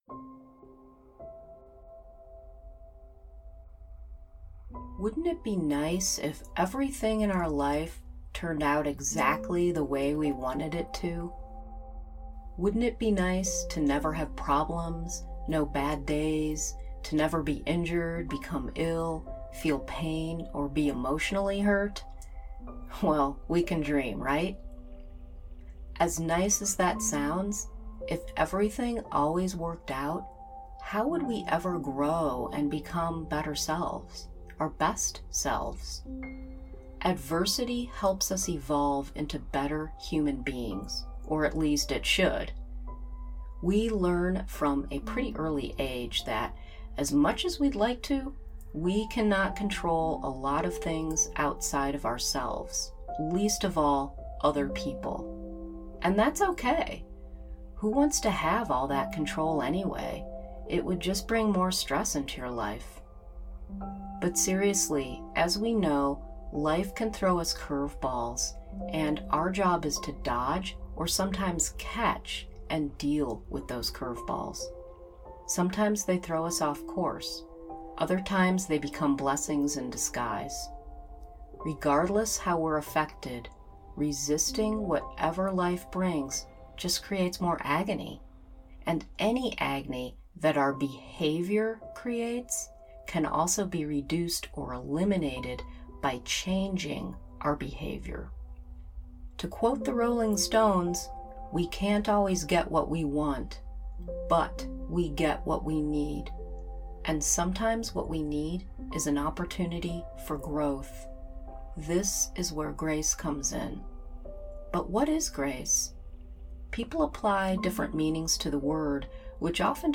Use this meditation to be grateful for life and help navigate when things don’t always happen the way you want or expect.